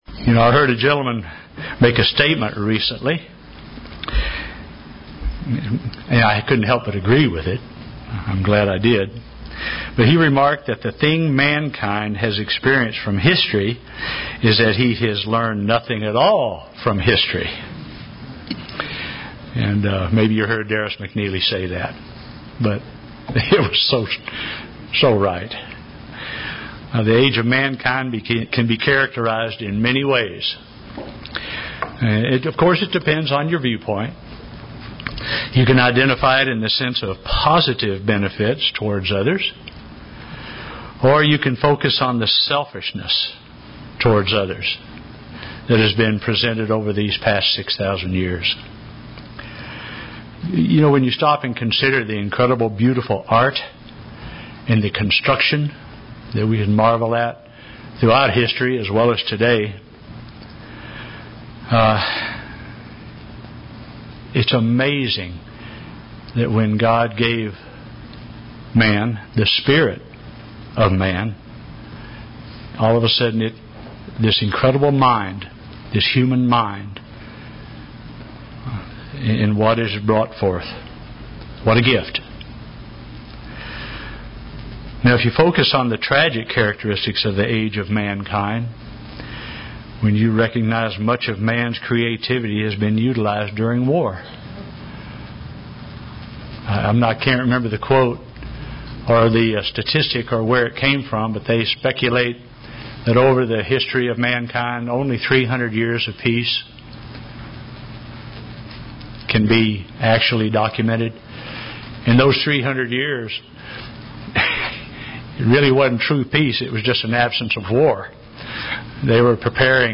Sermons
Given in Oklahoma City, OK